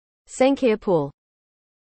韓国では、二重まぶたを「쌍꺼풀（サンゴプル）」と表現します。